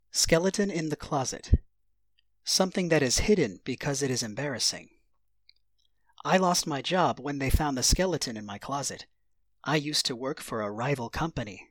ネイティブによる発音は下記のリン クをクリックしてください。